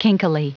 Prononciation du mot kinkily en anglais (fichier audio)
Prononciation du mot : kinkily